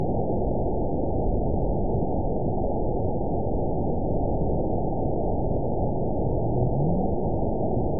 event 912491 date 03/27/22 time 20:33:11 GMT (3 years, 1 month ago) score 9.57 location TSS-AB04 detected by nrw target species NRW annotations +NRW Spectrogram: Frequency (kHz) vs. Time (s) audio not available .wav